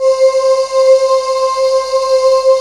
BREATH VOX 1.wav